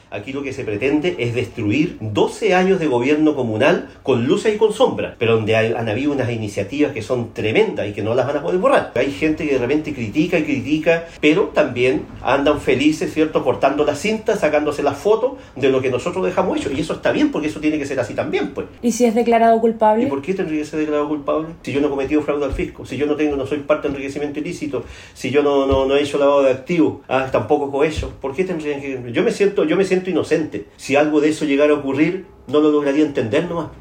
En el comedor de su casa, donde cumple la cautelar de arresto domiciliario total, con lápiz, papel y un vaso de agua, el exalcalde de Puerto Montt, Gervoy Paredes, conversó con Radio Bío Bío y defendió su inocencia afirmando que junto a su defensa tienen antecedentes que desestiman los cuatro delitos de corrupción que se le imputan al momento de liderar la capital regional de Los Lagos.